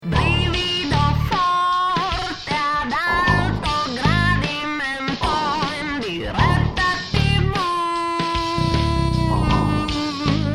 voce e campioni
pianoforte, campionatore e programmazione
batteria, chitarre trattate e campioni
bassi elettronici e contrabasso